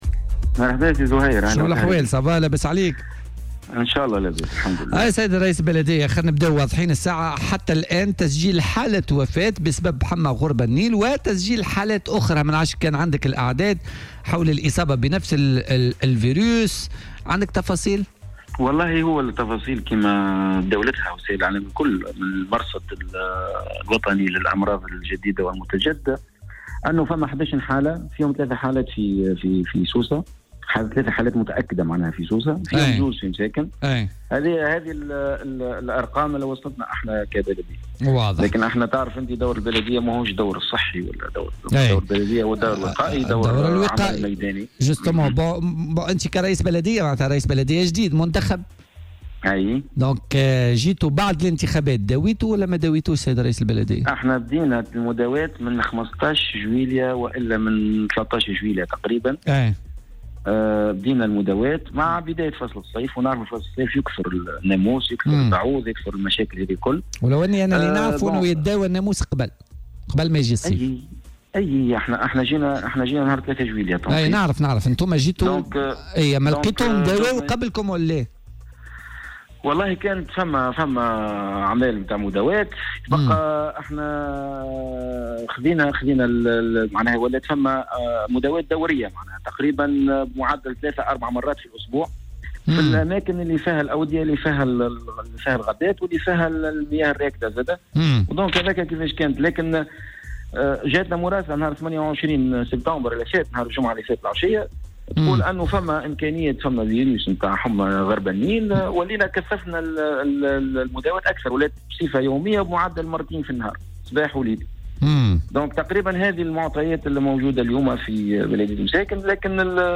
وأضاف خلال مداخلة هاتفية له اليوم الاثنين 1 أكتوبر 2018 في برنامج "بوليتيكا"، أن المعتمدية كثّفت من عمليات المداواة للأودية ومجاري المياه الراكدة، بمعدل مرتين في اليوم، كما أنه من المحتمل أن تتم غدا الثلاثاء عملية مداواة بالطائرة.